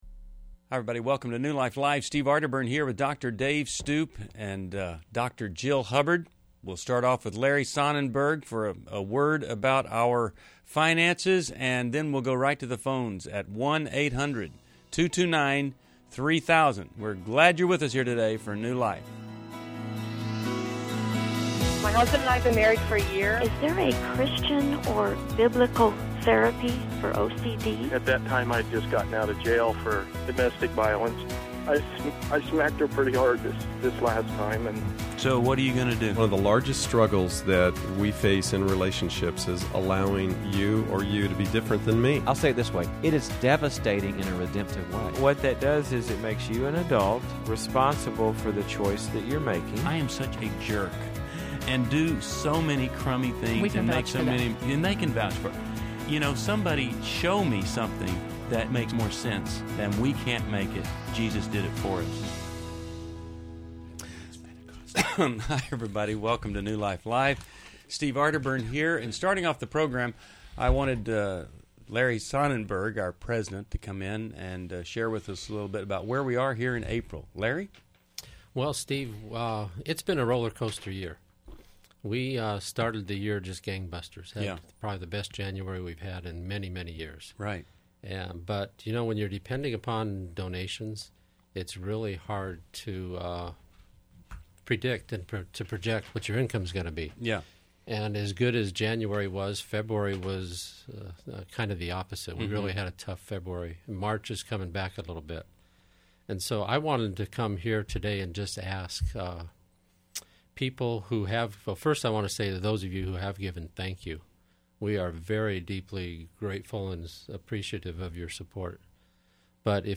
Explore marriage, parenting, dating, and adult children issues on New Life Live: April 13, 2011, with expert insights and real caller questions.